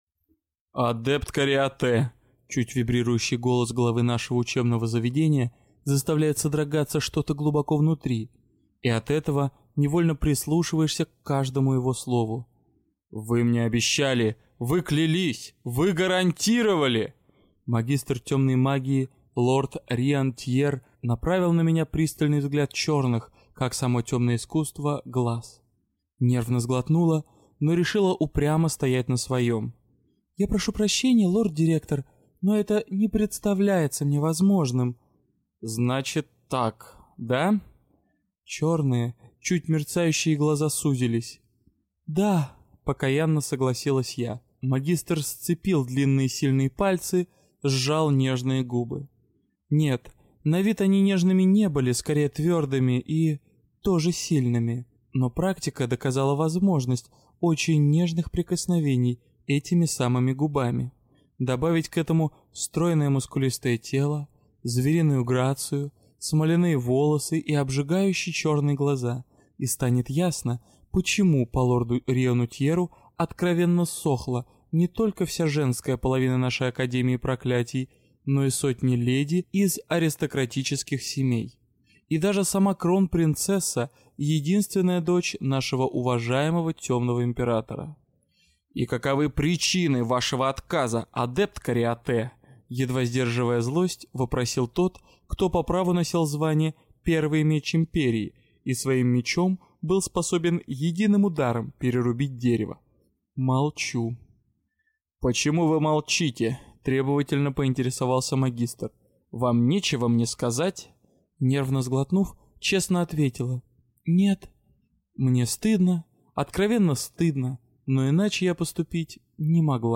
Аудиокнига Урок второй: Не ввязывайся в сомнительные расследования - купить, скачать и слушать онлайн | КнигоПоиск